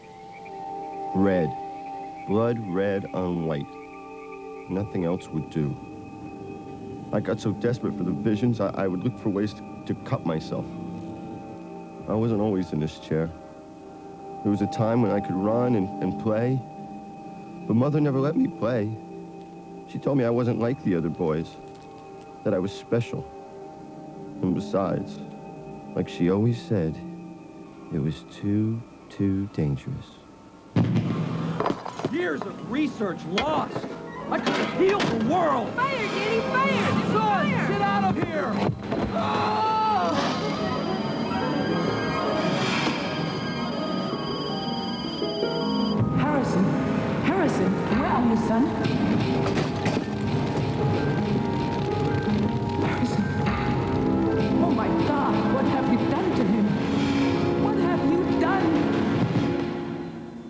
It is much, much better than the previous english track you heard. There's some hiss though...
There are no artifacts of compression (unlike the previous one that sounded metallic)